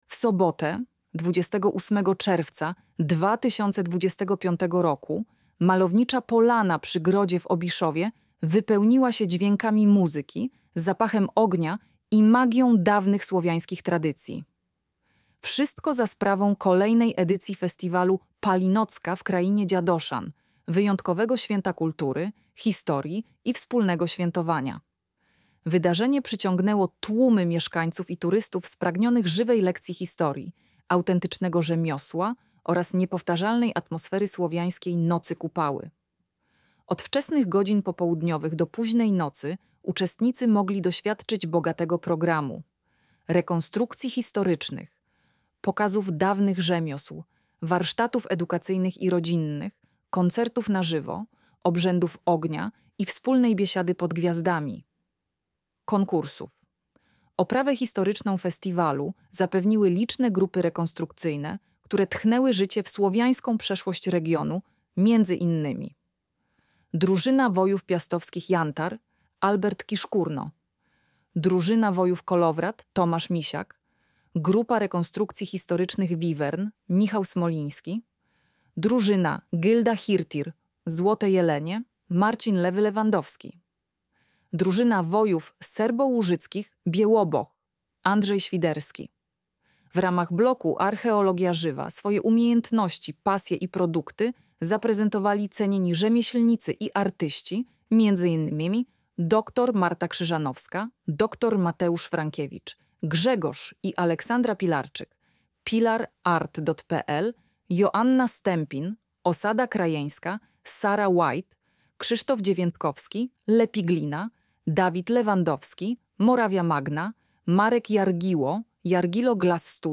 Lektor-Palinocka.mp3